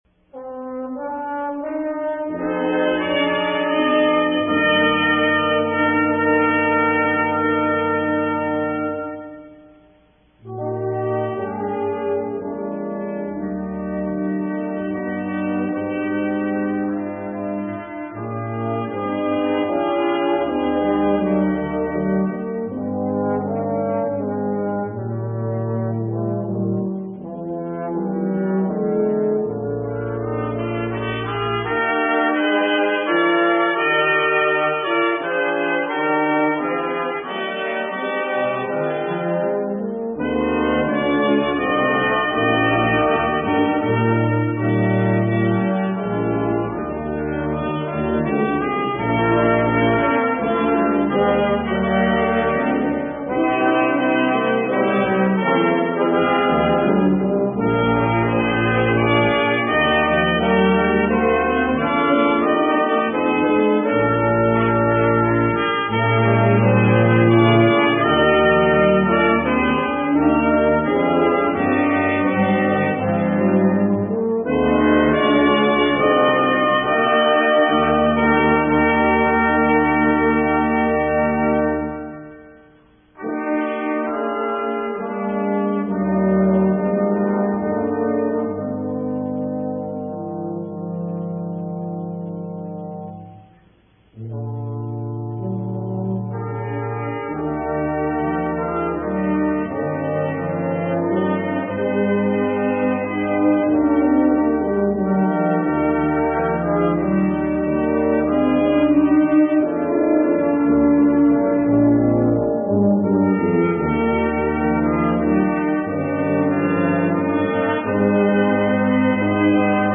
(brass bnd opt parts)
Brass Ensemble
Key Eb Meter 3-4, 4-4
Brass sextet Form Song (ternary)